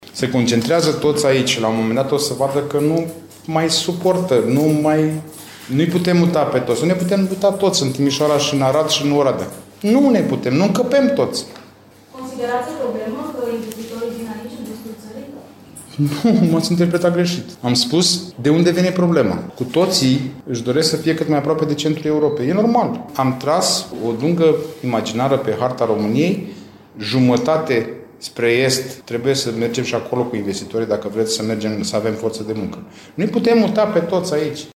Declarația a fost făcută de ministrul Marius Budăi, prezent la deschiderea Târgului Locurilor de Muncă organizat la Timișoara de Agenția Județeană pentru Ocuparea Forței de Muncă.